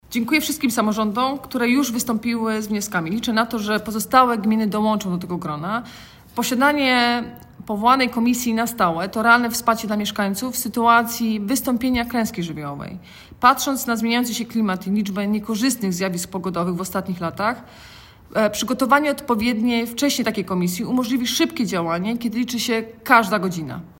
Mówi Anna Żabska Wojewoda Dolnośląska.